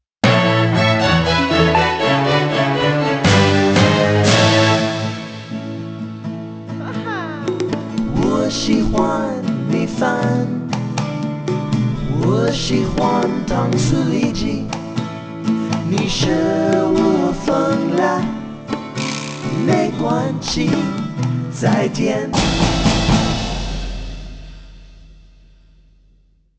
Traditional (ethnic)